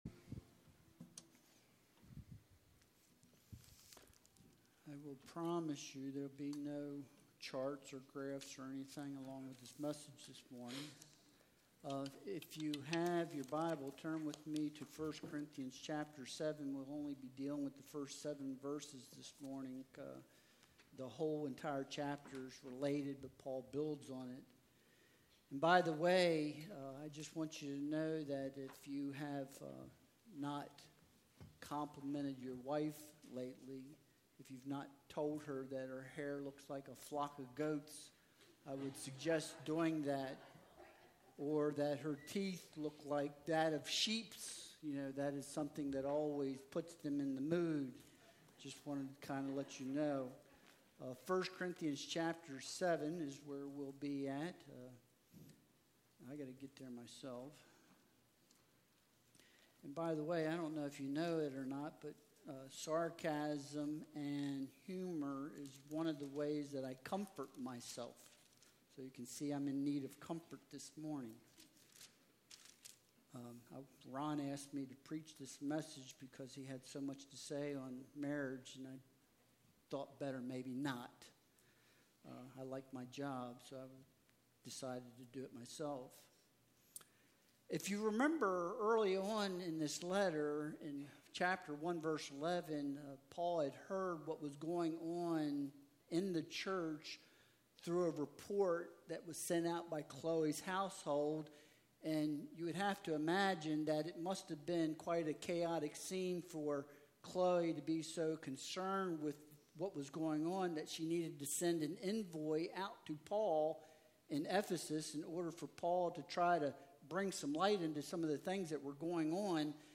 Passage: 1 Corinthians 7.1-7 Service Type: Sunday Worship Service Download Files Bulletin « Advent – Hope